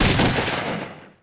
Shotgun_SingleShot_1.ogg